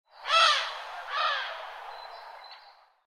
Eurasian-collared-dove-bird-warnin-call.mp3